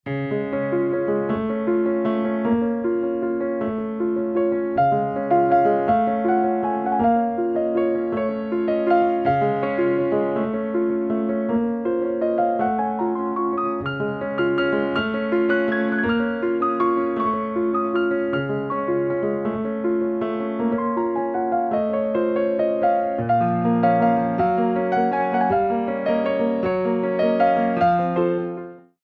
29 Original Piano Pieces for Ballet Class
Adage
slow 6/8 - 2:46